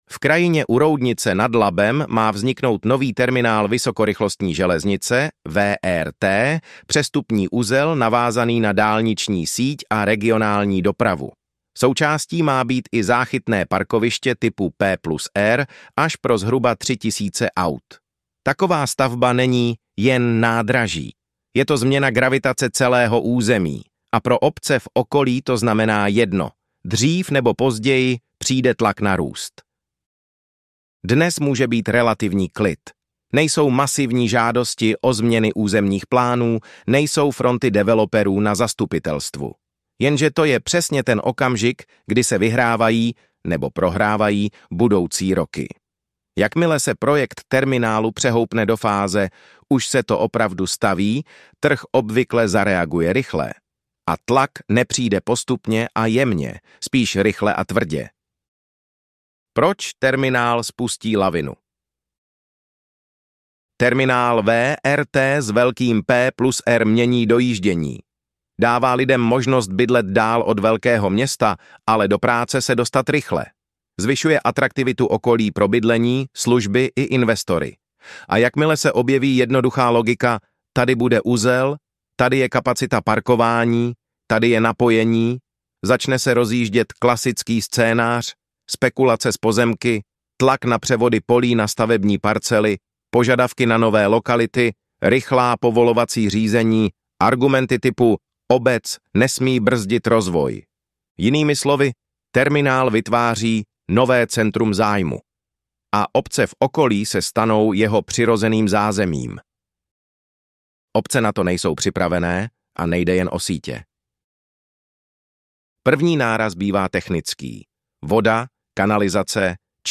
Audio verze článku – vhodné na poslech při rutinní práci.